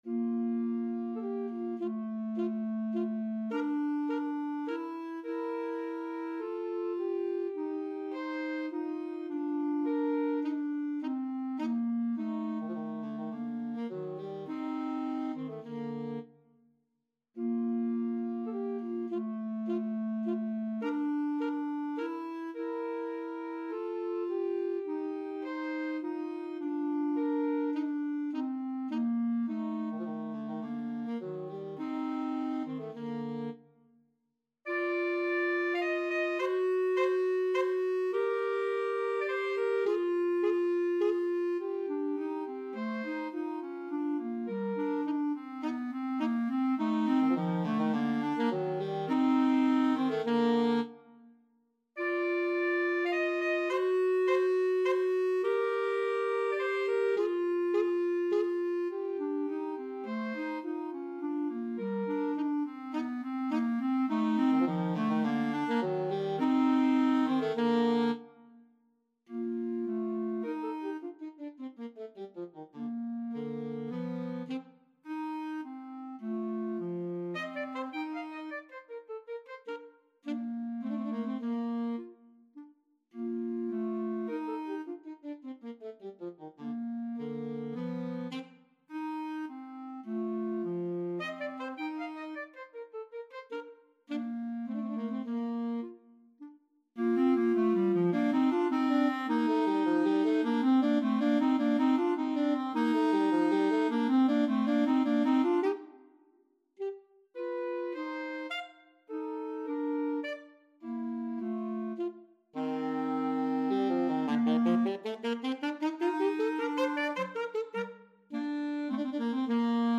Alto SaxophoneClarinet
3/4 (View more 3/4 Music)
Con Grazia = c. 104